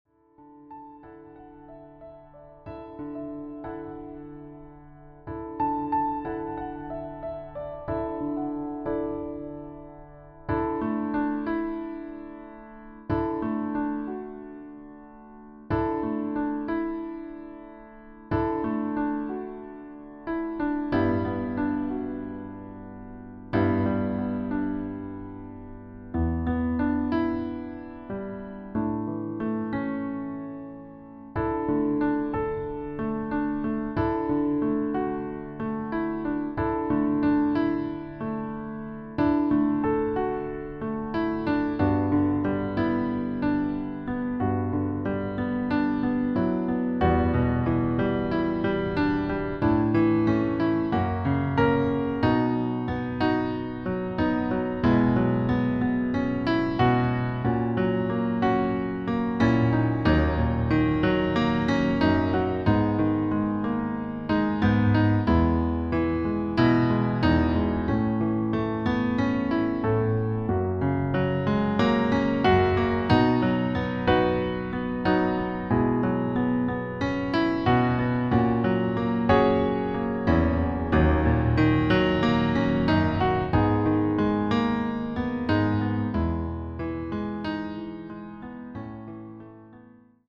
Flügeleinspielung
Demo in D Dur:
• Das Instrumental beinhaltet NICHT die Leadstimme
Klavier / Piano